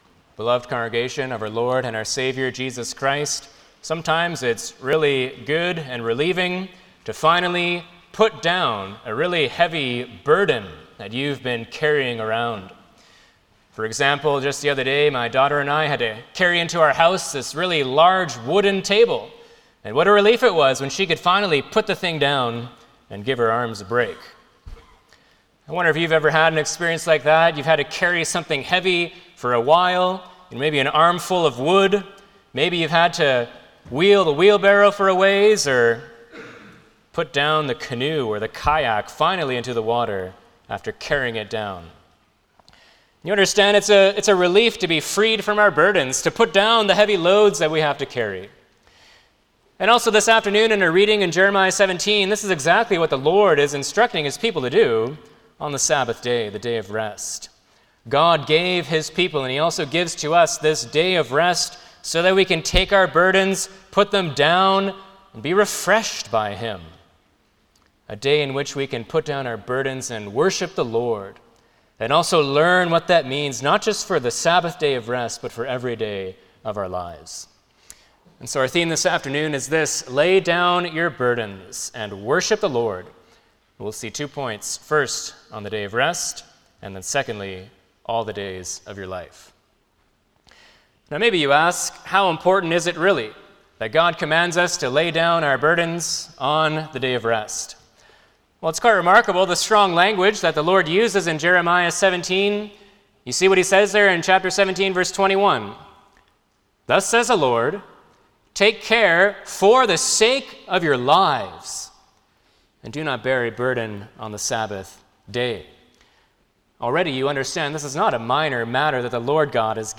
Passage: Lord’s Day 38 Service Type: Sunday afternoon
07-Sermon.mp3